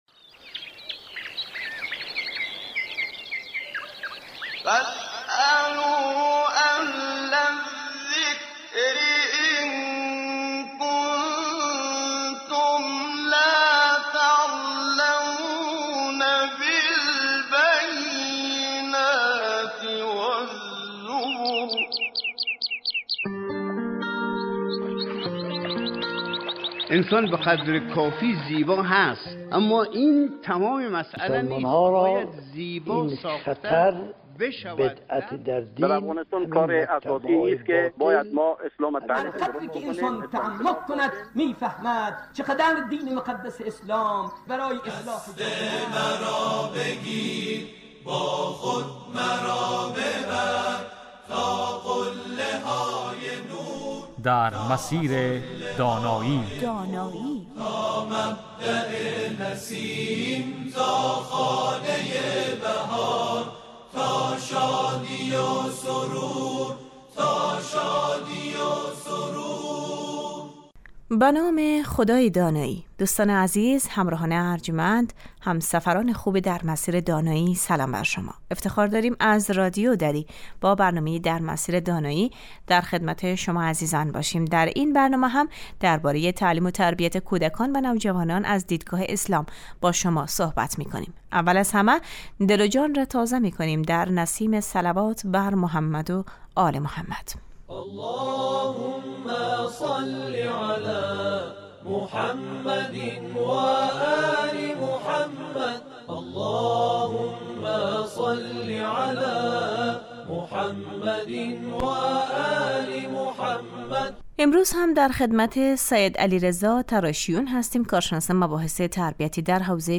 این برنامه 20 دقیقه ای هر روز بجز جمعه ها ساعت 11:35 از رادیو دری پخش می شود